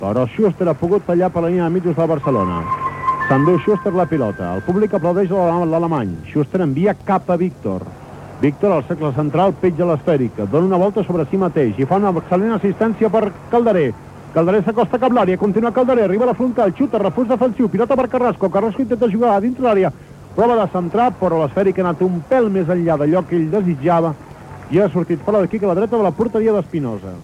Narració d'una jugada d'atac del Barça del partit FC Barcelona-Hércules.
Esportiu